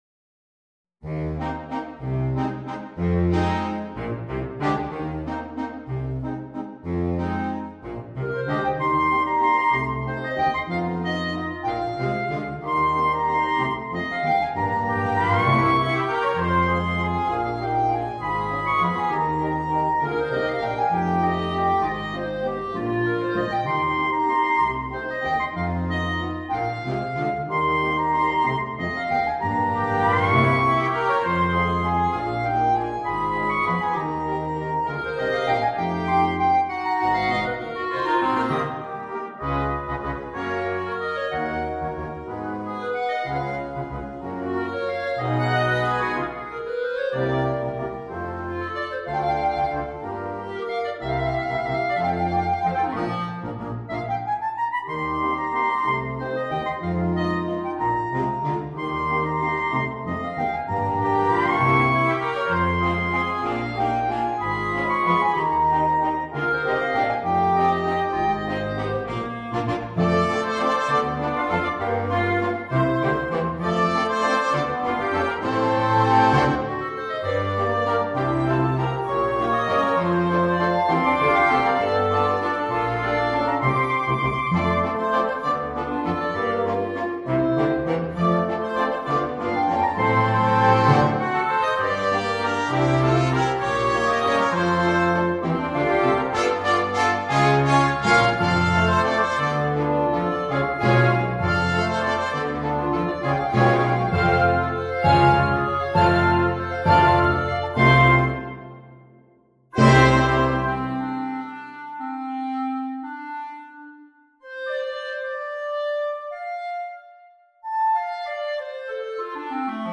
Per clarinetto e banda
Il clarinetto protagonista in questo brano d’effetto.